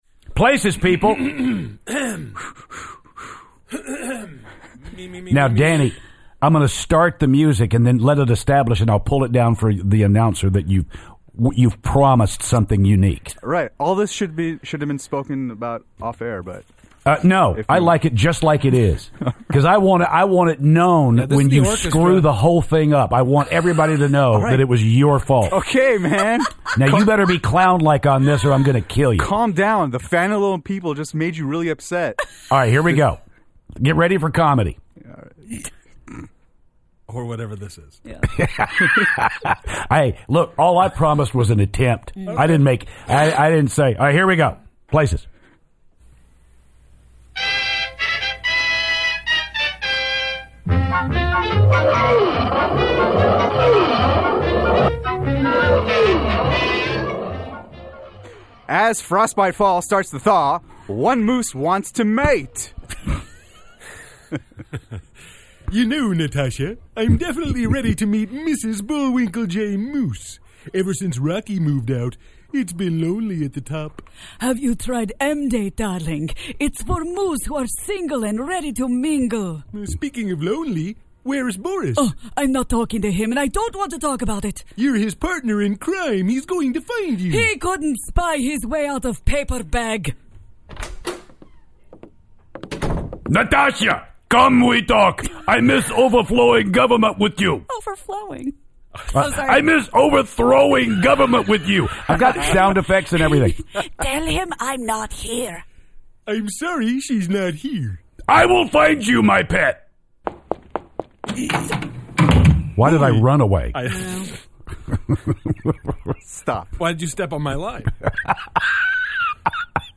Rocky and Bullwinkle Sketch
The guys attempt comedy with "Rocky and Bullwinkle"!